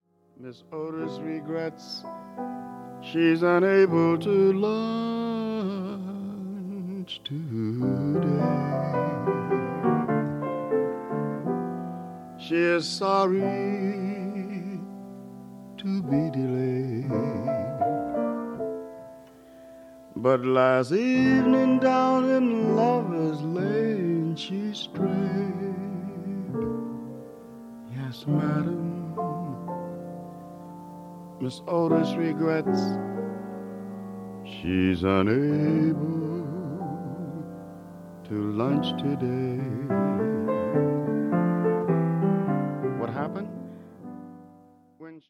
ラジオ番組のために録音された音源なのだそう。